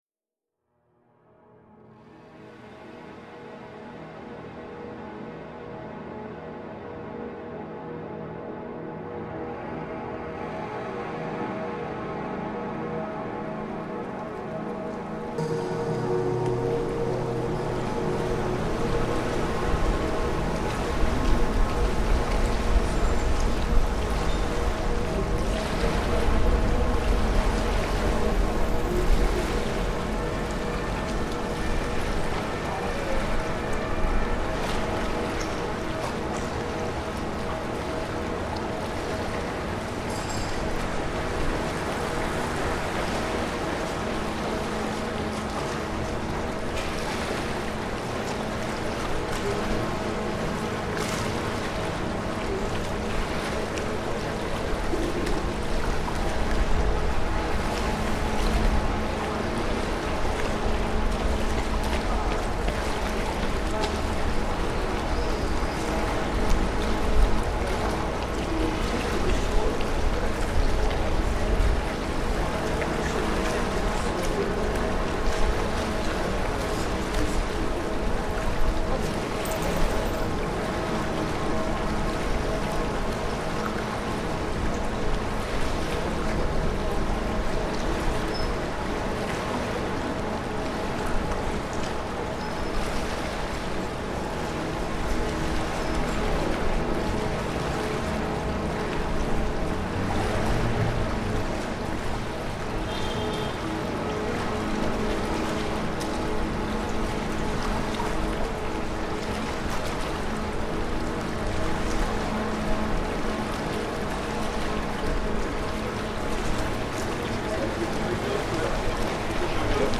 Paris Pont Neuf soundscape reimagined